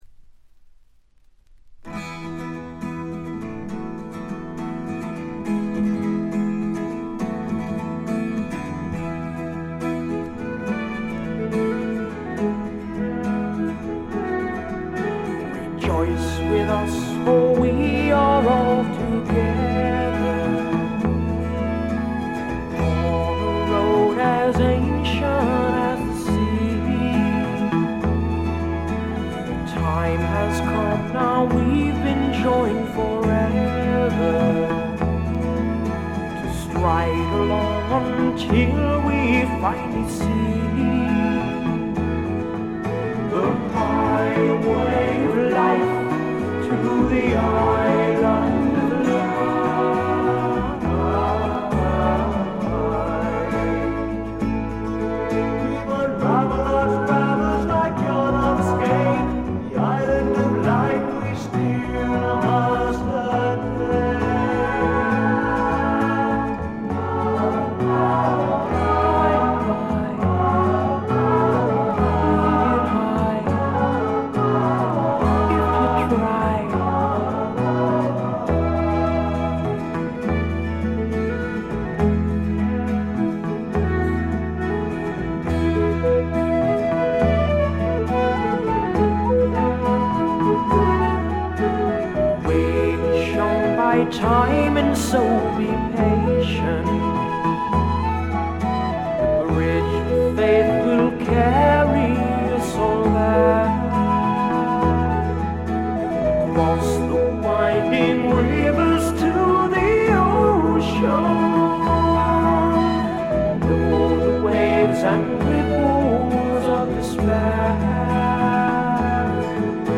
米国産クラシカル・プログレッシブ・フォークの名作です。
試聴曲は現品からの取り込み音源です。